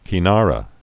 (kē-närə)